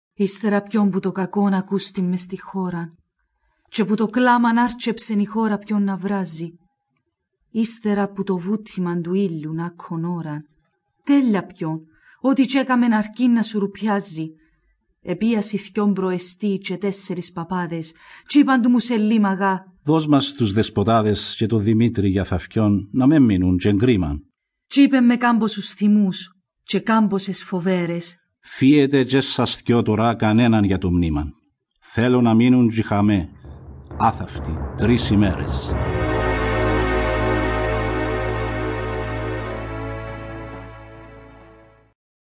Απαγγελία